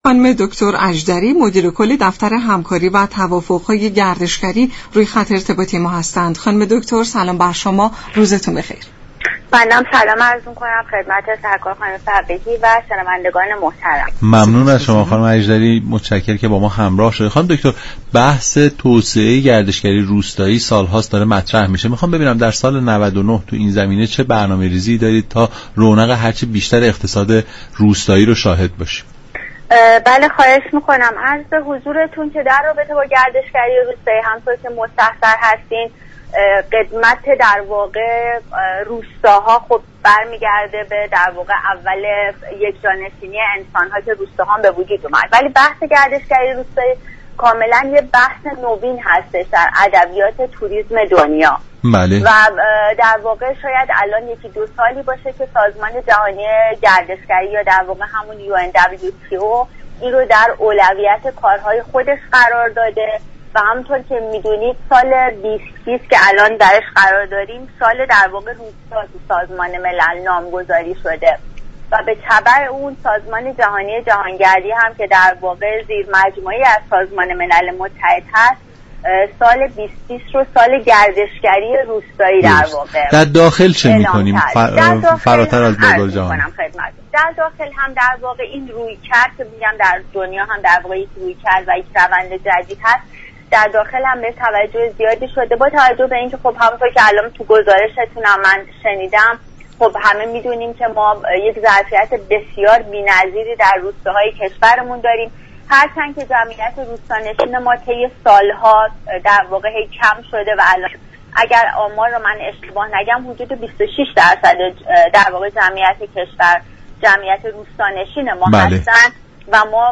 مدیر كل دفتر هماهنگی و توافق‌های ملی معاونت گردشگری سازمان میراث فرهنگی در برنامه نمودار گفت: اگرچه امروز روستانشینان حجم كمی از جمعیت ایران را تشكیل می دهند اما همین میزان نیز دارای ظرفیت عظیم گردشگری است.